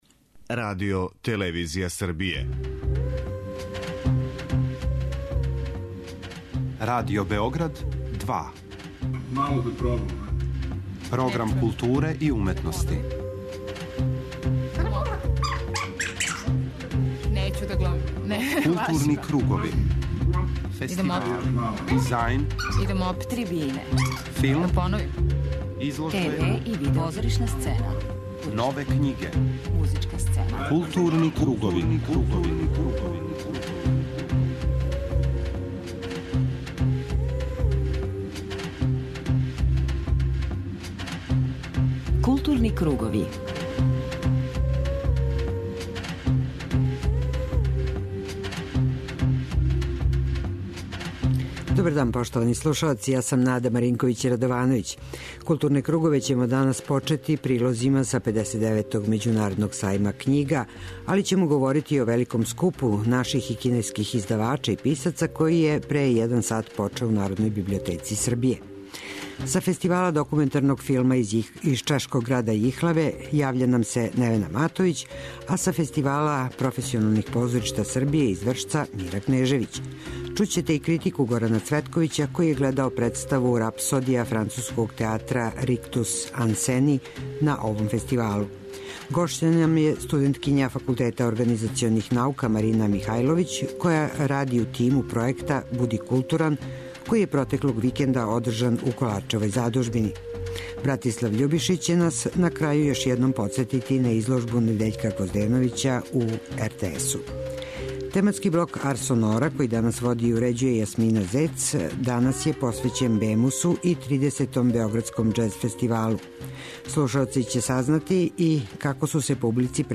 Пратићемо шта се дешава на Фестивалу документарног филма у чешком граду Јихлави и чути разговор са глумцима и критику представе "Рапсодија" театра Rictus anseny из Француске, која је изведена у оквиру Међународног фестивала класике и нове класике "Вршачка позоришна јесен".
преузми : 52.61 MB Културни кругови Autor: Група аутора Централна културно-уметничка емисија Радио Београда 2.